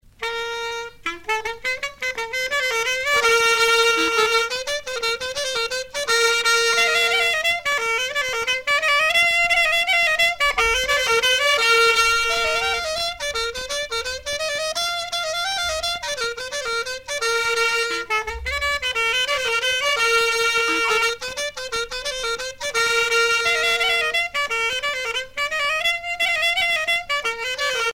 danse : gavotte bretonne ; danse : plinn
Pièce musicale éditée